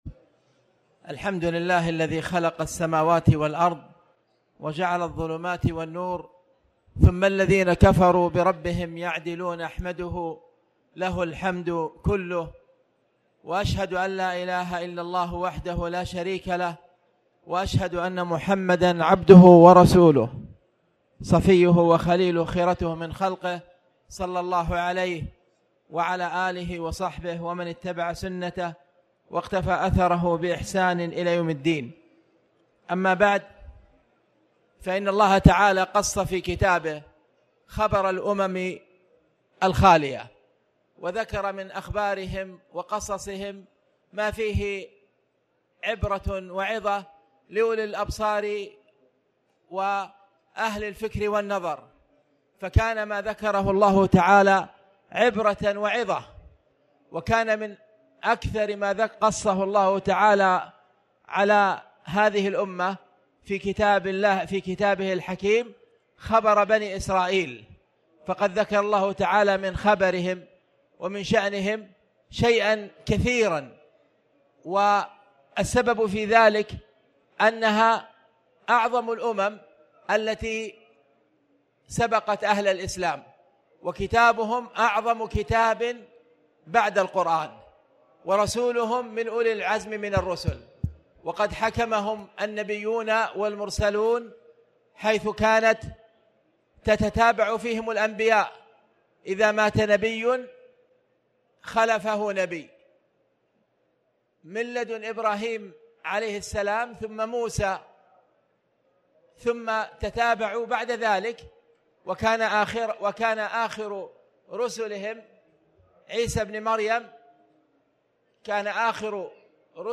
تاريخ النشر ١٧ رمضان ١٤٣٩ هـ المكان: المسجد الحرام الشيخ